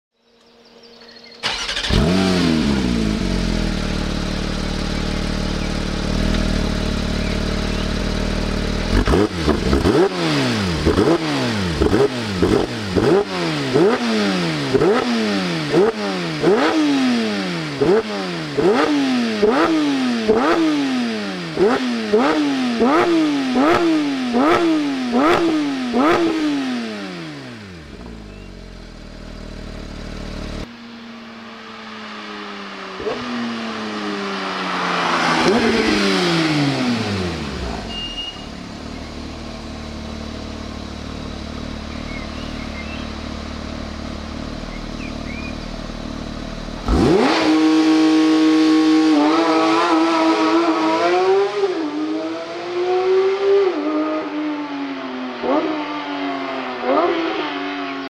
4.0 510ch GT3 PDK